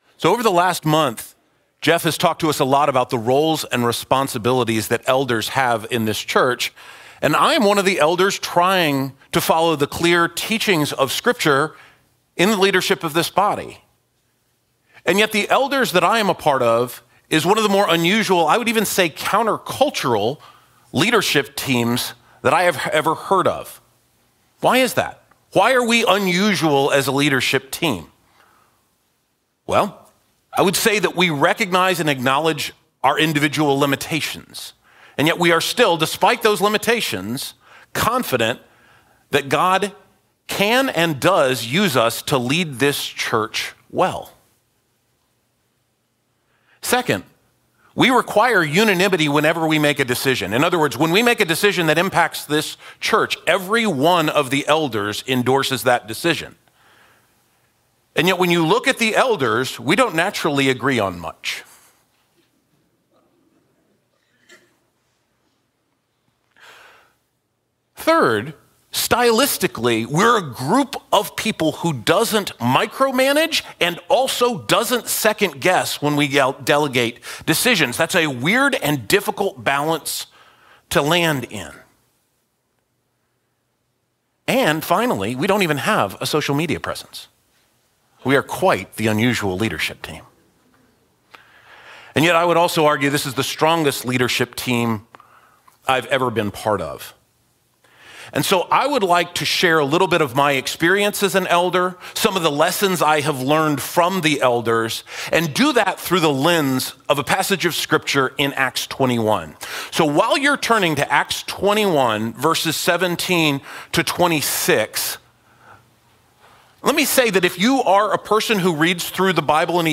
Dunwoody Community Church